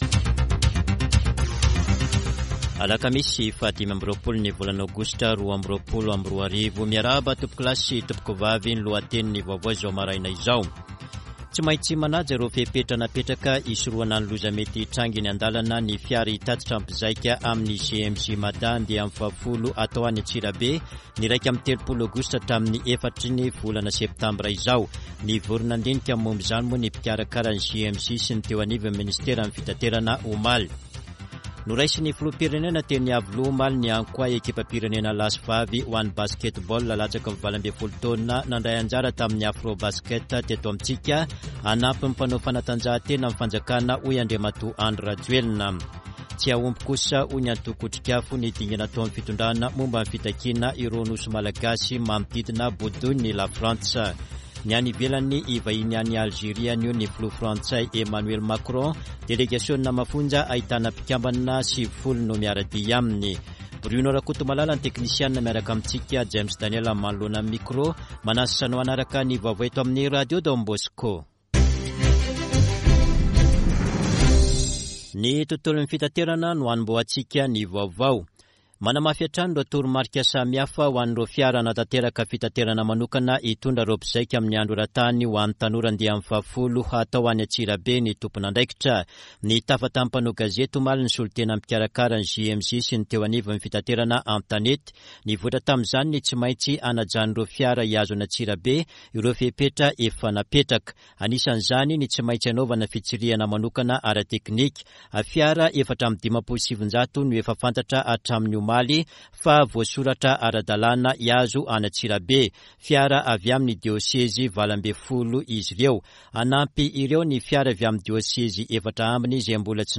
[Vaovao maraina] Alakamisy 25 aogositra 2022